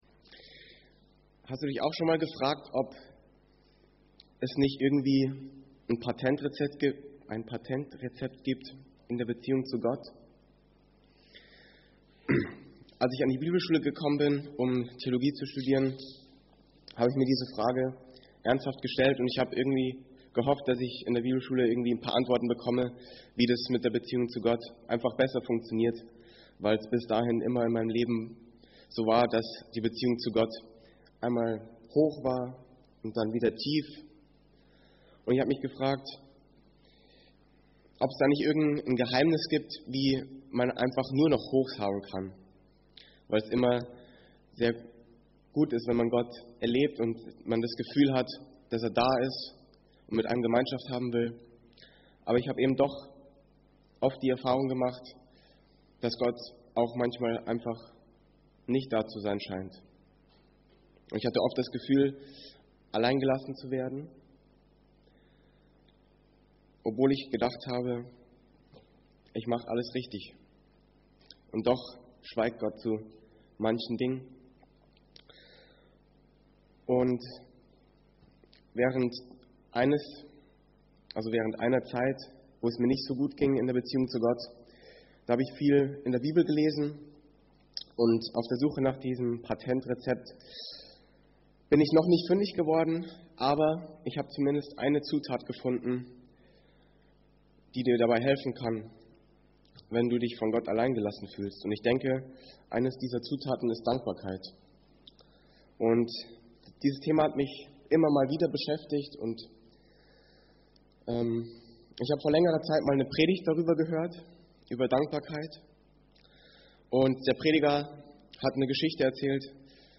Evangelisch-freikirchliche Gemeinde Andernach - Predigt anhören